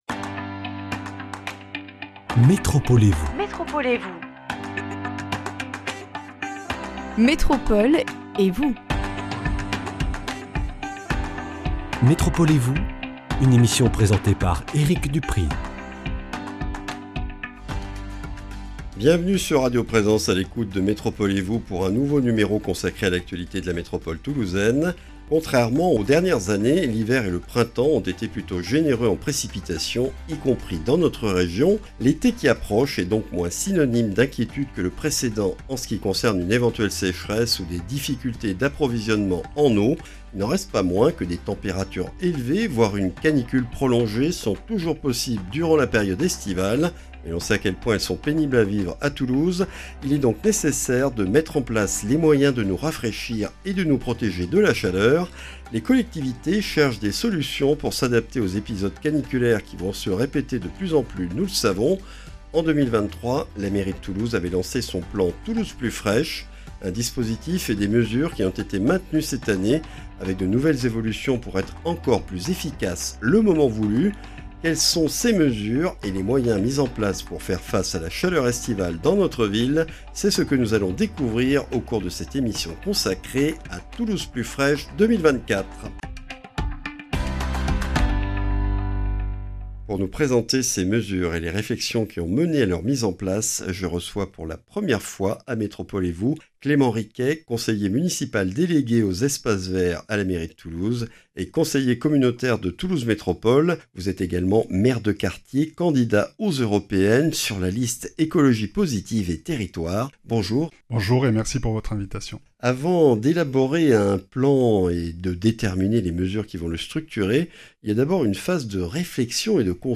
Clément Riquet, conseiller municipal délégué à la mairie de Toulouse (espaces verts), secrétaire de la commission Écologie, Développement Durable et Transition énergétique de Toulouse Métropole, nous en présente les objectifs et les mesures principales.